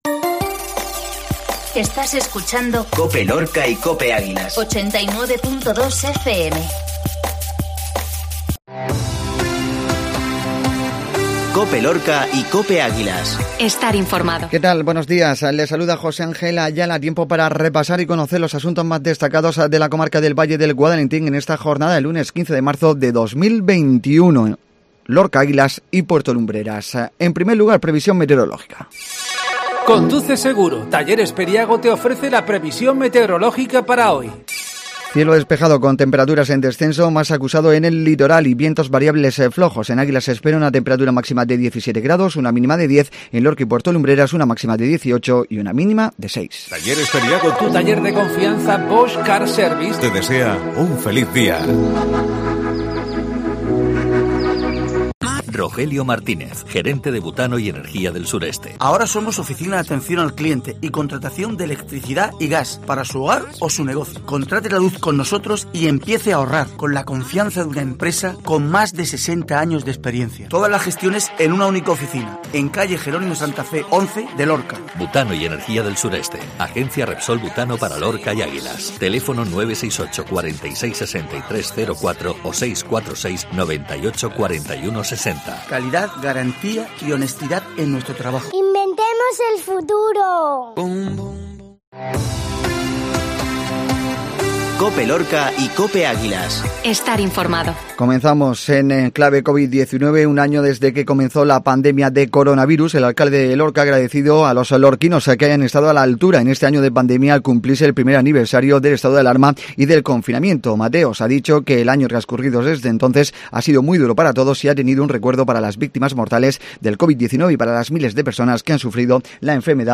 INFORMATIVO MATINAL LUNES